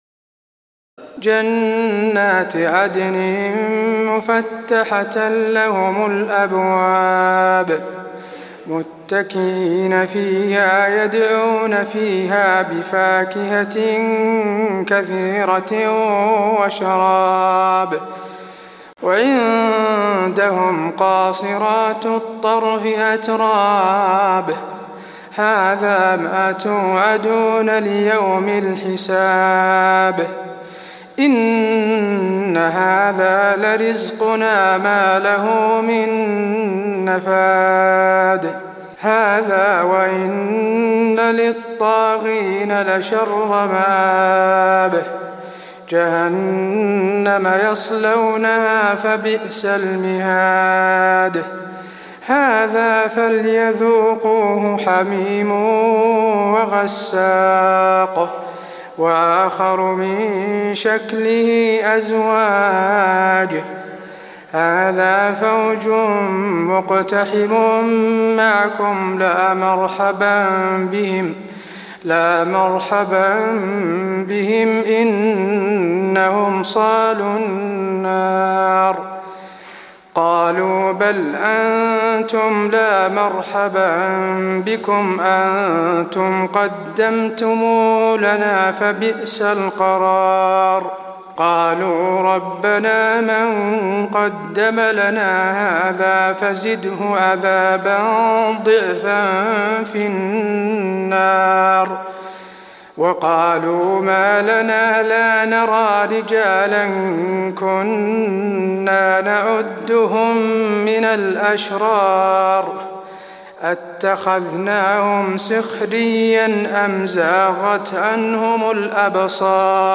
تراويح ليلة 22 رمضان 1423هـ من سور ص (50-88) و الزمر (1-31) Taraweeh 22 st night Ramadan 1423H from Surah Saad and Az-Zumar > تراويح الحرم النبوي عام 1423 🕌 > التراويح - تلاوات الحرمين